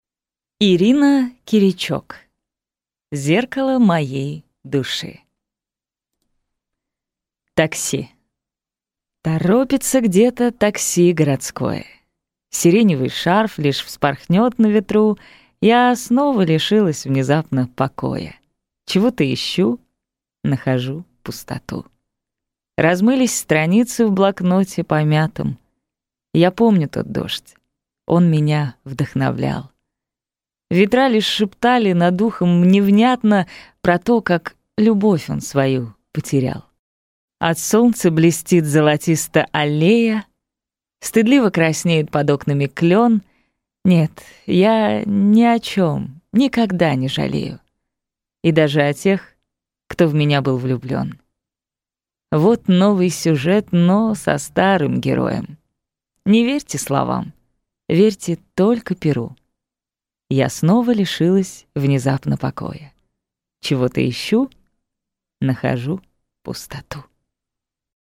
Аудиокнига Зеркало моей души | Библиотека аудиокниг